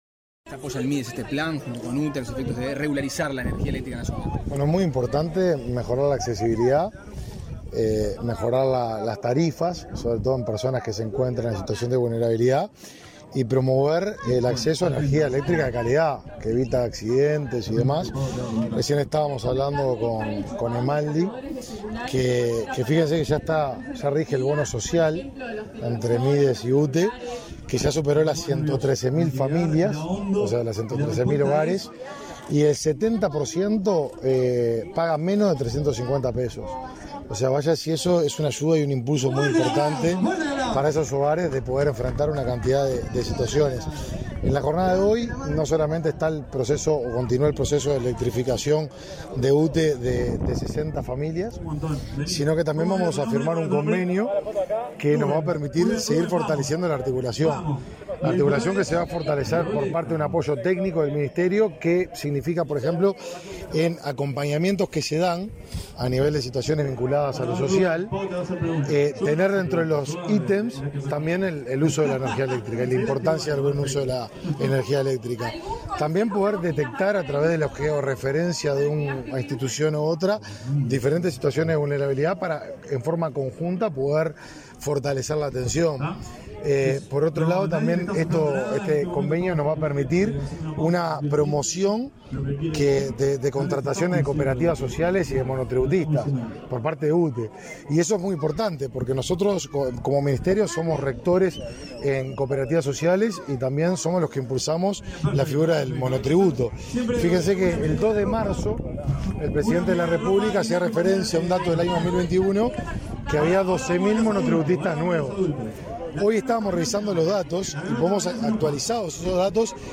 Declaraciones a la prensa del ministro de Desarrollo Social, Martín Lema
Tras el acto correspondiente, el ministro Martín Lema, efectuó declaraciones a la prensa.